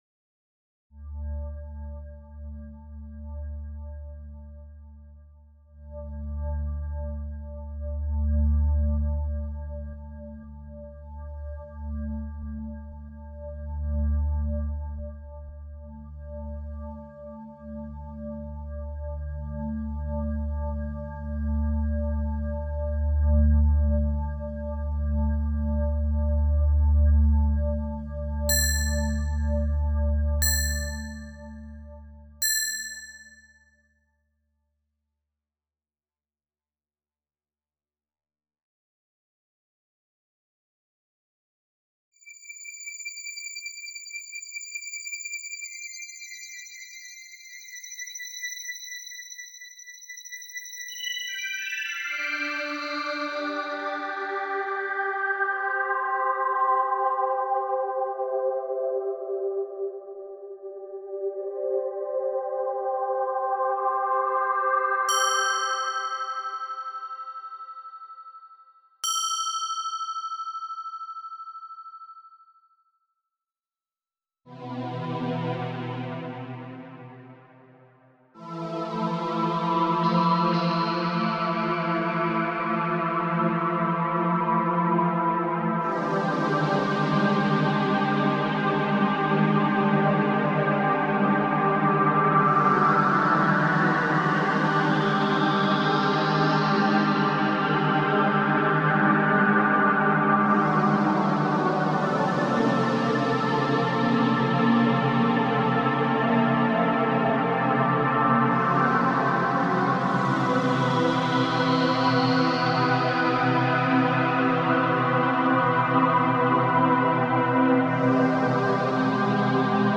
synth trance new wave ambient orchestral
electric techno